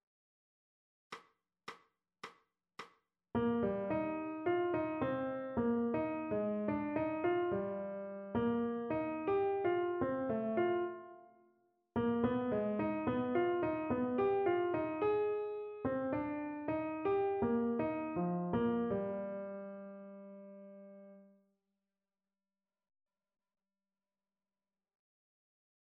Jazz i música moderna
A1-dictat-melodic-jazz-moderna-audio-24-06.mp3